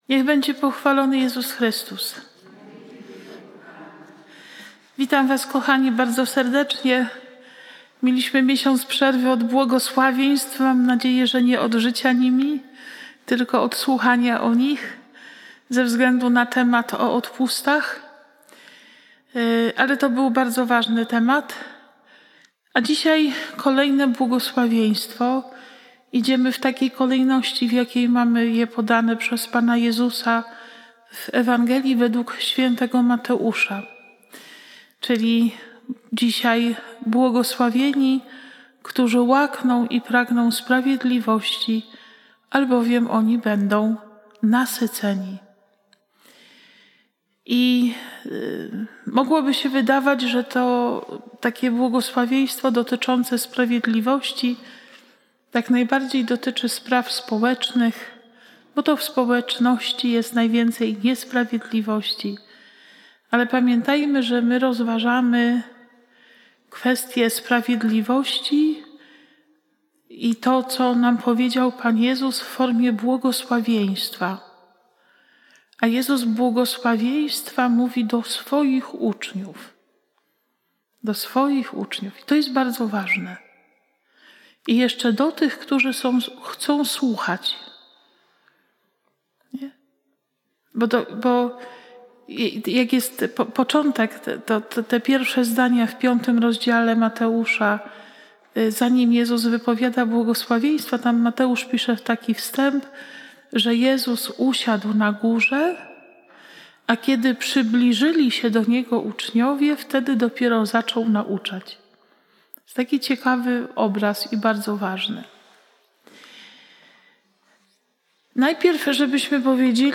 konferencja